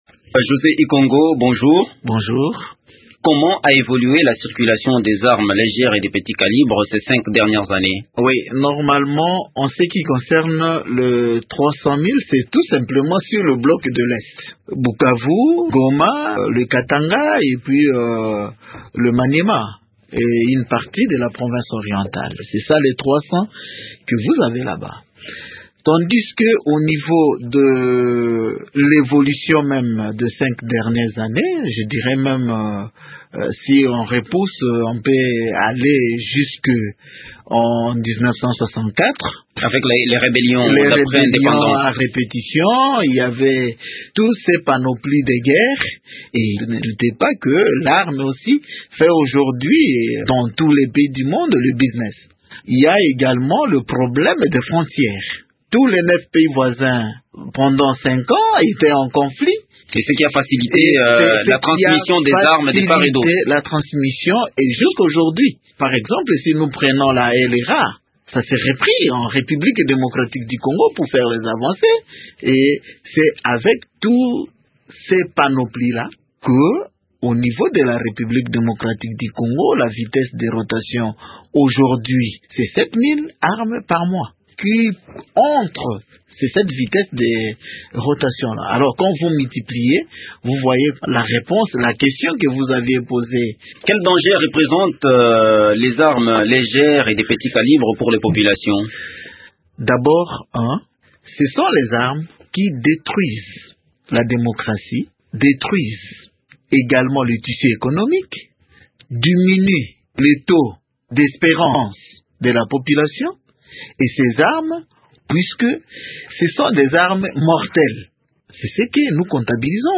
Le secrétaire permanent de la Commission nationale de contrôle des armes légères et de petit calibre répond.
José Ikongo est interrogé